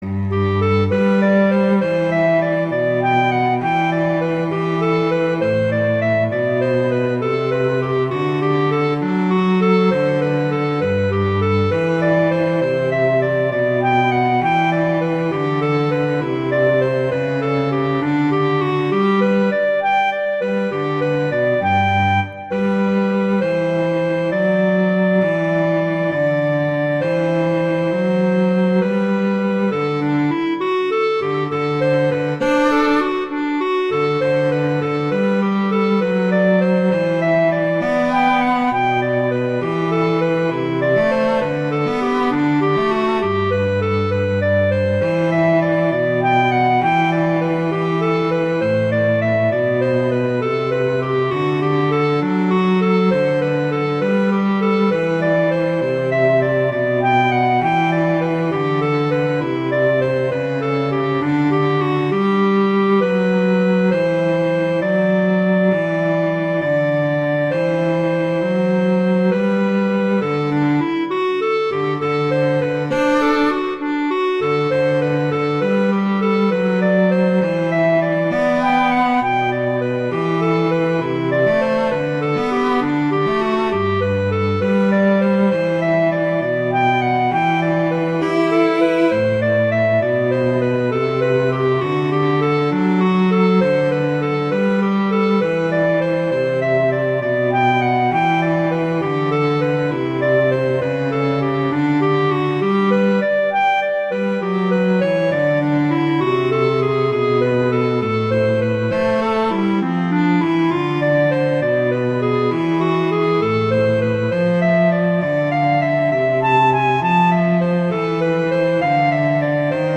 arrangements for clarinet and cello